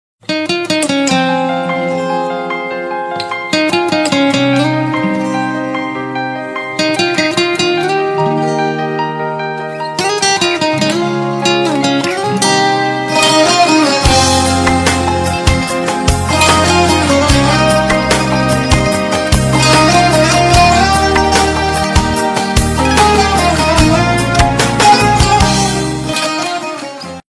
Categoría Clasicos